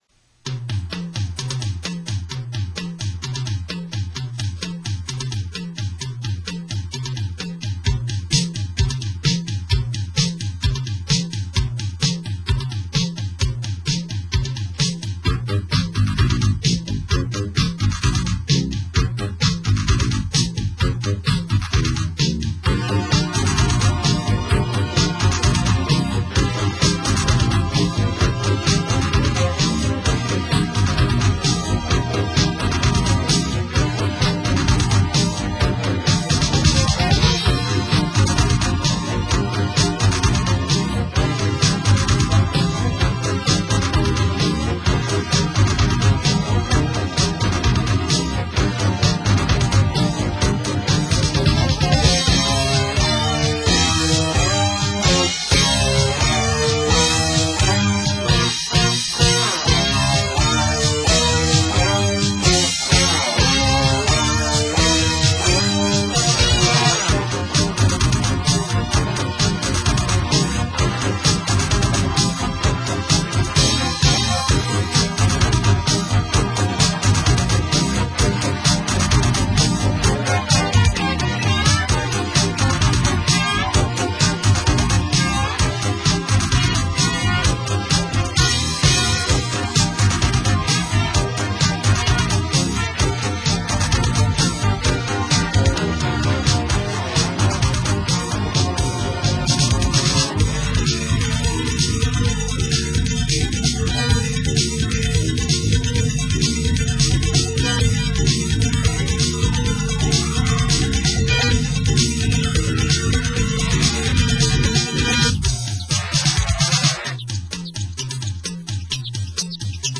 アフリカの狩猟民族がテクノ音楽で武装したようなイメージ。